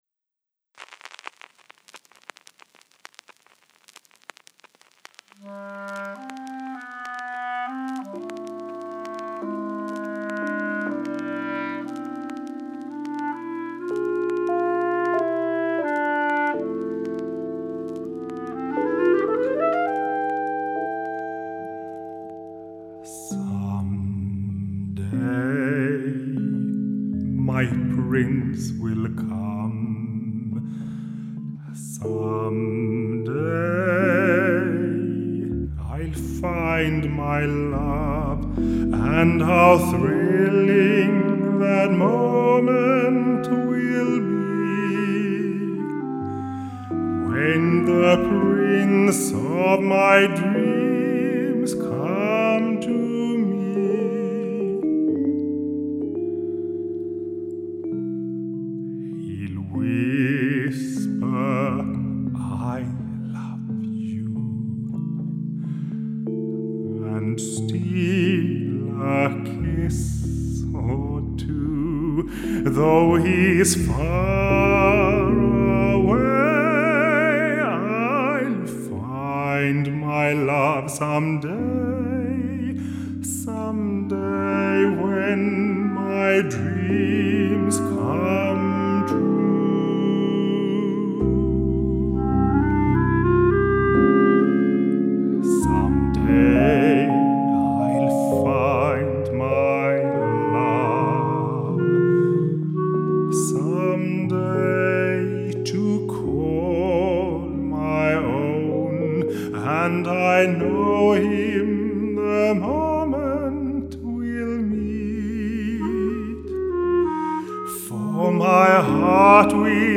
Swedish tenor
Christmas EP